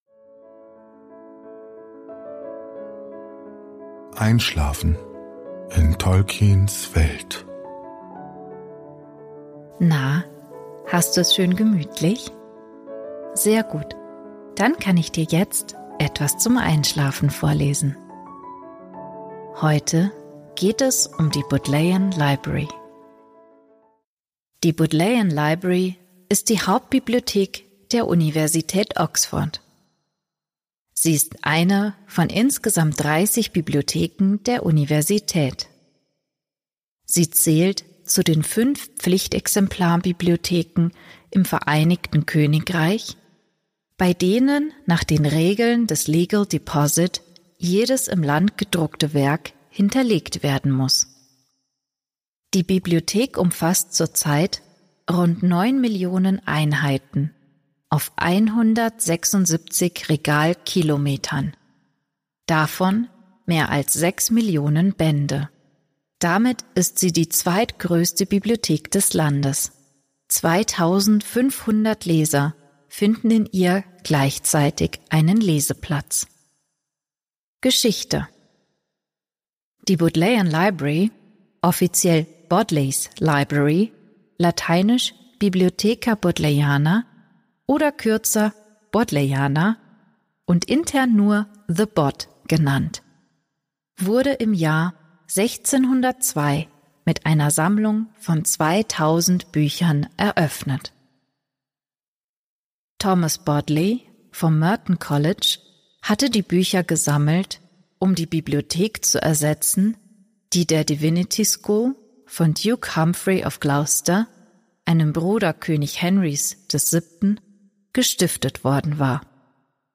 Gutenachtgeschichten aus der Ardapedia